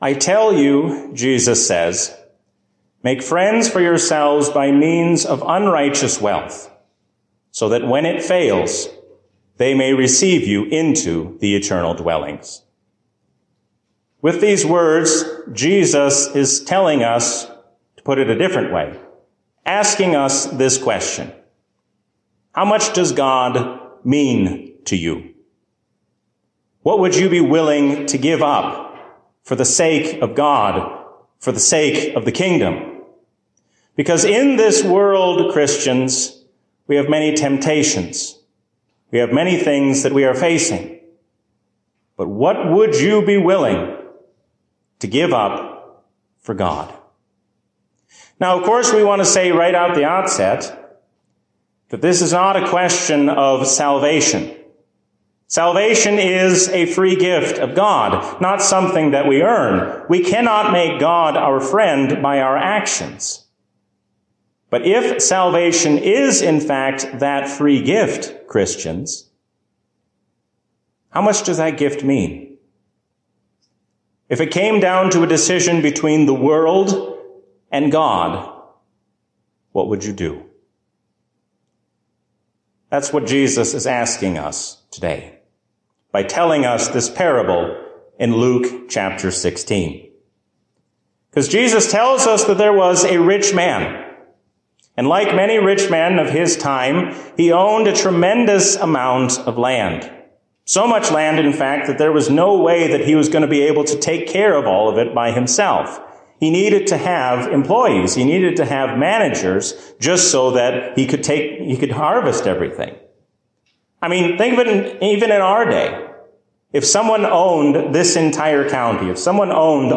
A sermon from the season "Pentecost 2023." If we are forced to choose between our job and Jesus, we must hold fast to Him.